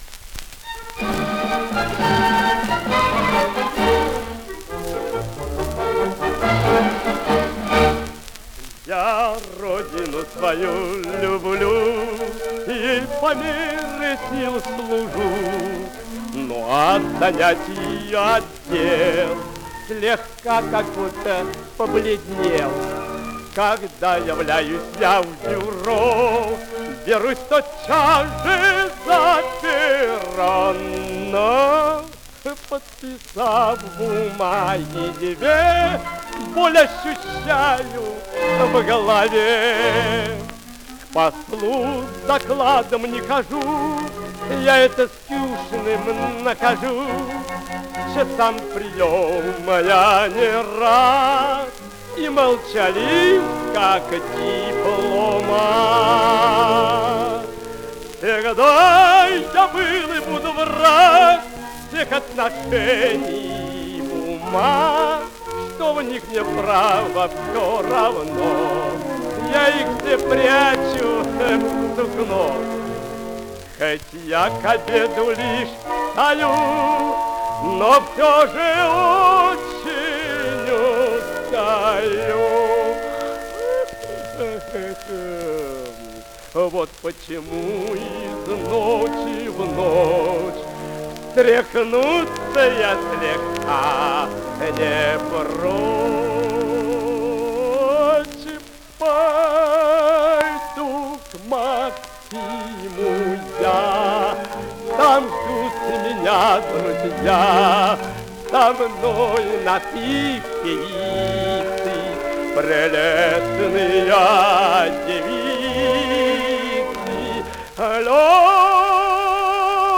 Певческий голосбаритон
Жанрыопера, оперетта
М. И. Днепров, арии и дуэты